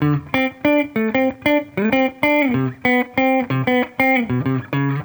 Index of /musicradar/sampled-funk-soul-samples/95bpm/Guitar
SSF_TeleGuitarProc1_95D.wav